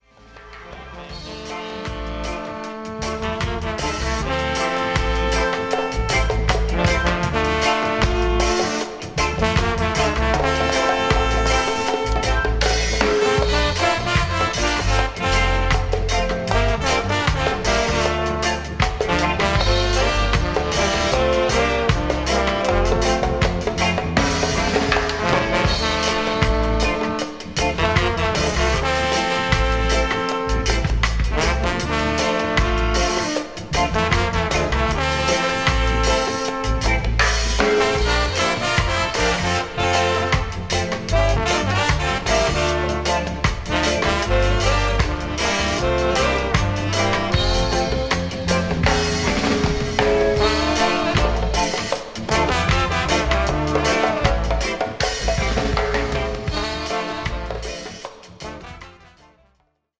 1995年のライブ・アルバム。